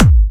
VEC3 Bassdrums Trance 63.wav